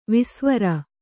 ♪ visvara